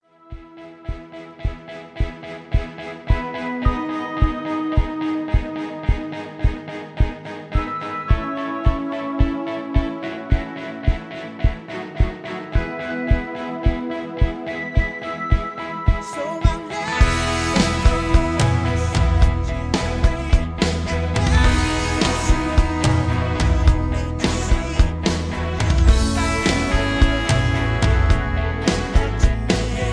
(Key-E)